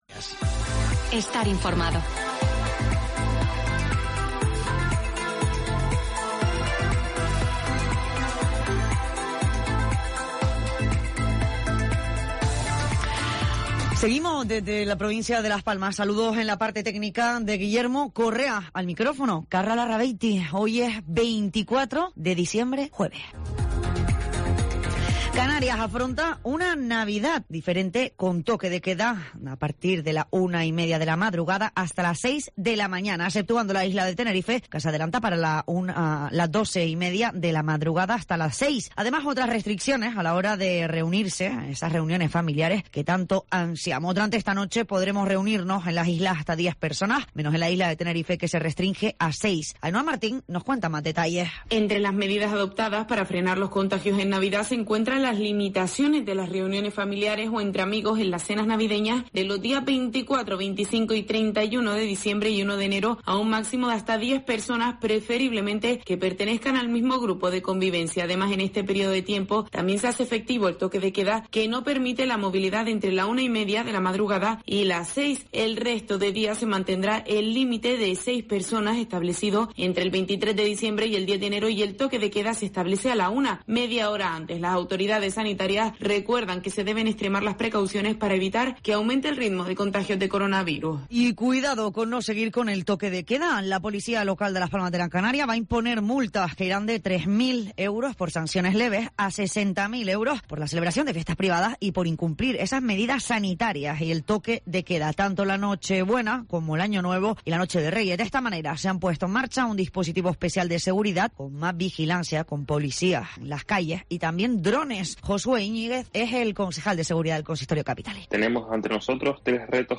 Informativo local 24 de Diciembre del 2020